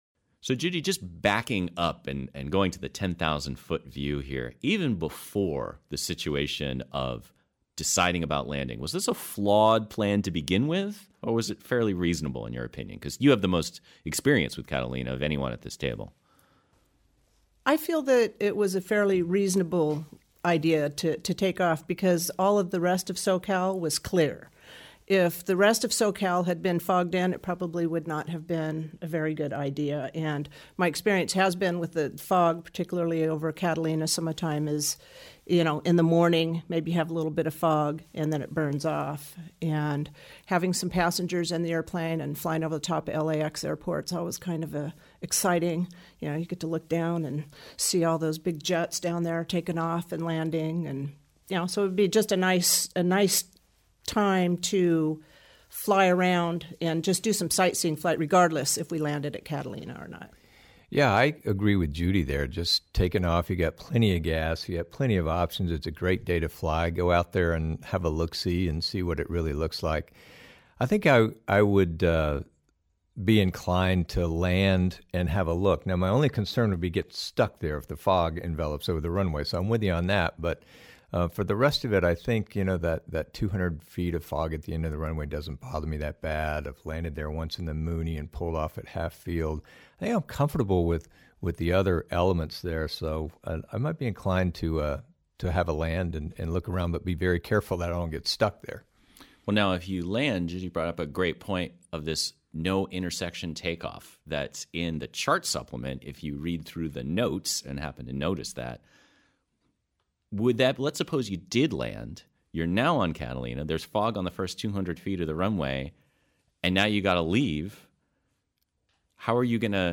Foggy_Catalina_Round table2.mp3